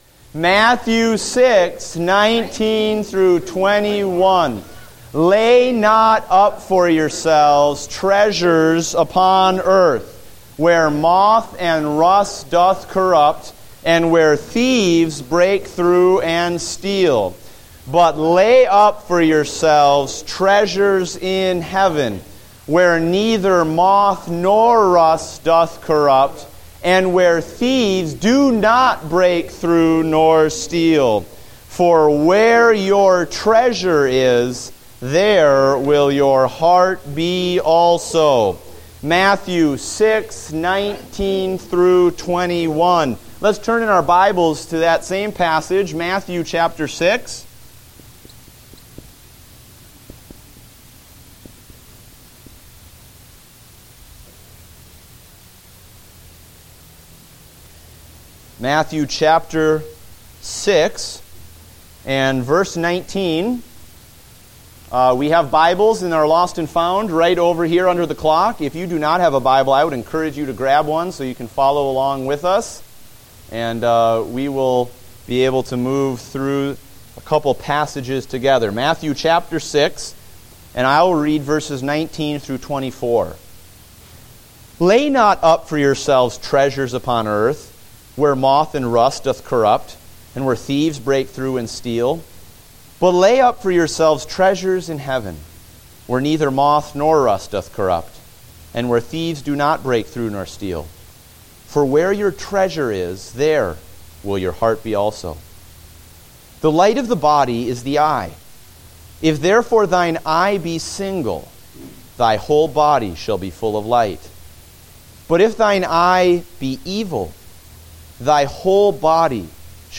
Date: August 16, 2015 (Adult Sunday School)